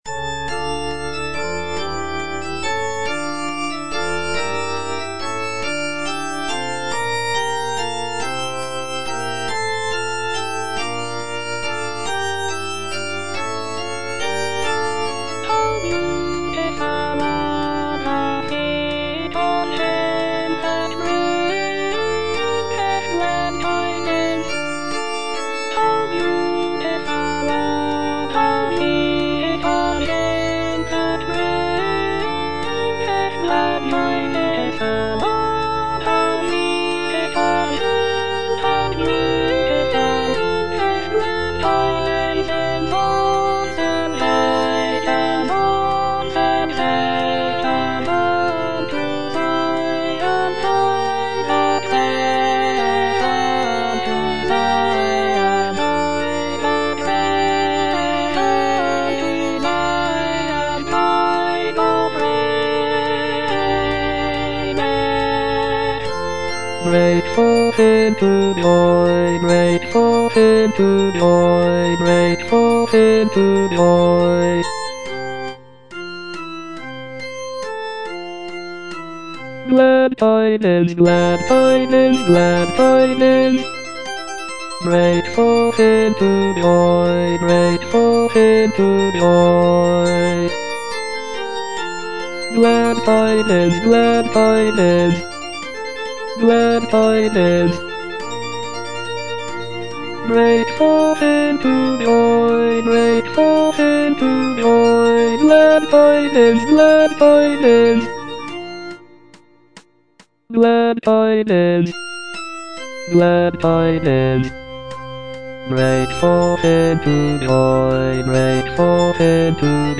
Bass (Voice with metronome) Ads stop
aria